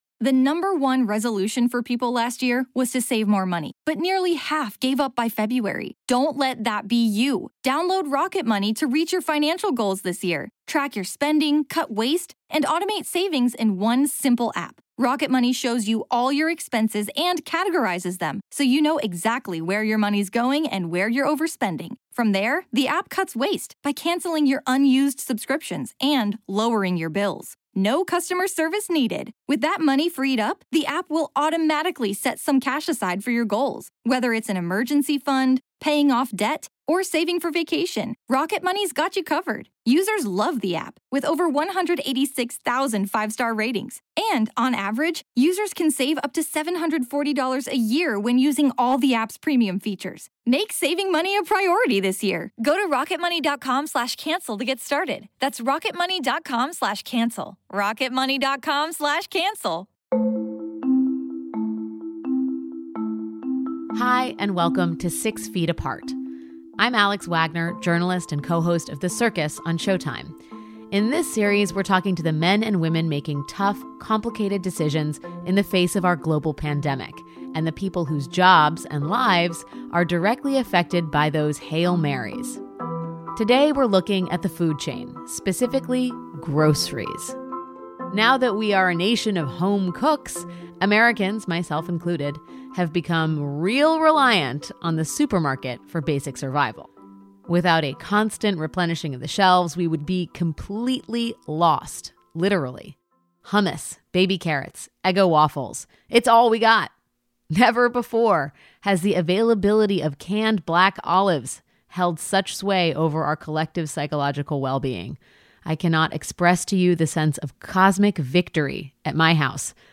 In the first episode, Alex talks to two essential workers about what it takes to bring you your groceries in the middle of a global pandemic.